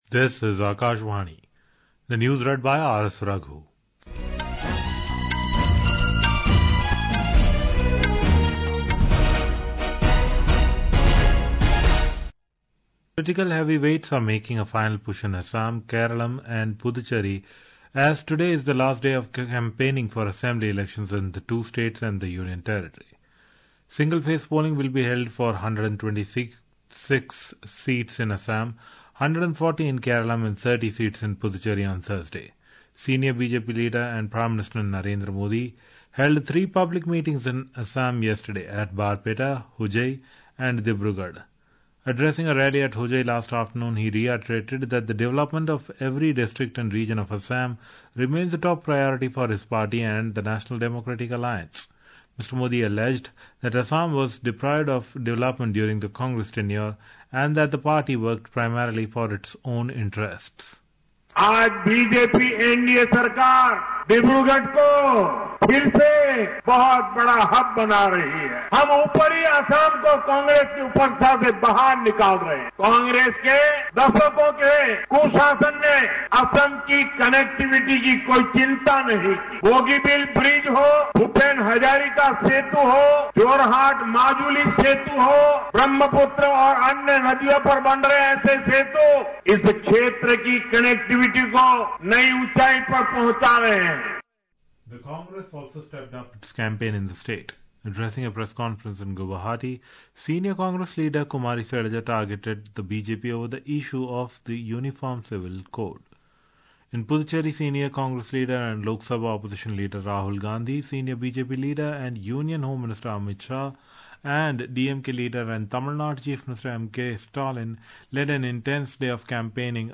રાષ્ટ્રીય બુલેટિન
प्रति घंटा समाचार